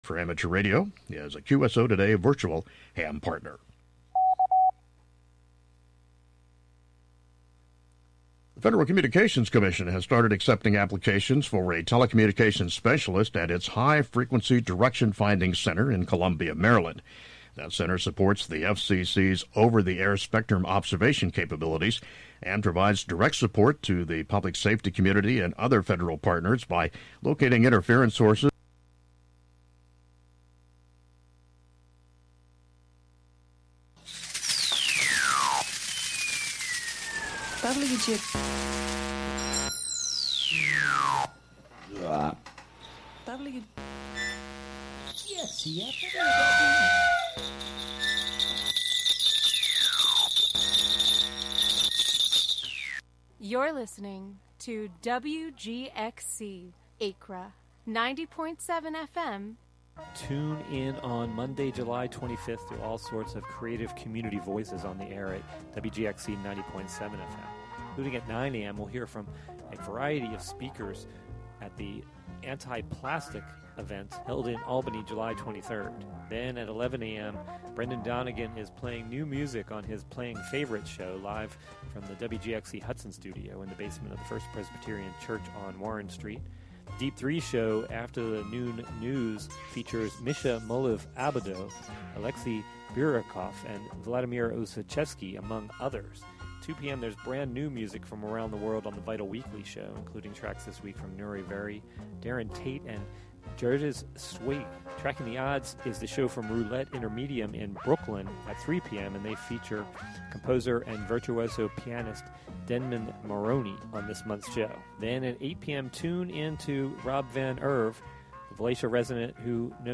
Beyond Plastics coordinated a national day of action on July 23 to protest the massive amount of single-use plastic by having people take plastic back to local supermarkets. In Albany, Zero Waste and Watervliet Huddle held an event at Market 32 on Delaware Ave.
The "WGXC Morning Show" is a radio magazine show featuring local news, interviews with community leaders and personalities, reports on cultural issues, a rundown of public meetings and local and regional events, with weather updates, and more about and for the community, made mostly through volunteers in the community through WGXC.